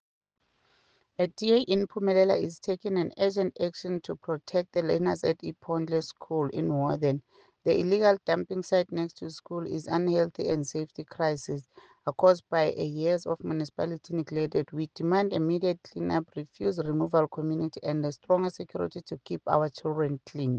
English soundbite by Cllr Ntombi Mokoena and